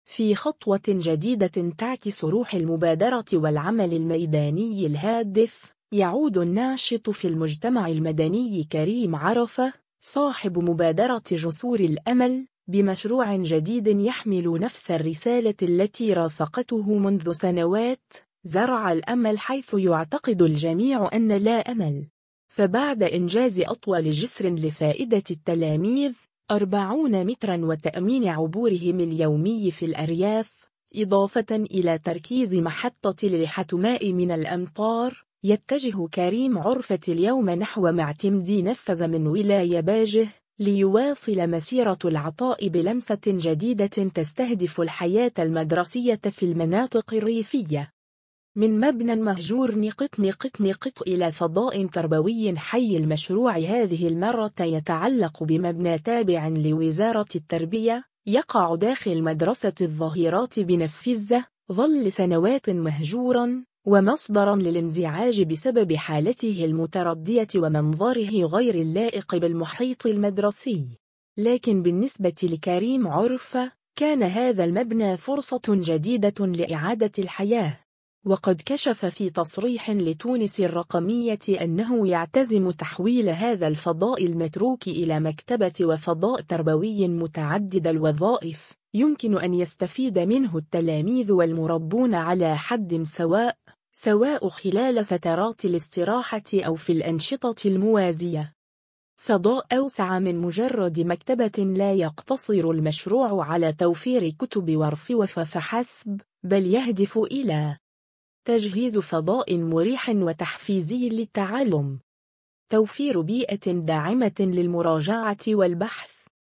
بصمة أمل في نفزة: مبنى مهجور ينبض بالحياة ليصبح فضاءً تربويًا واعدًا [تصريح]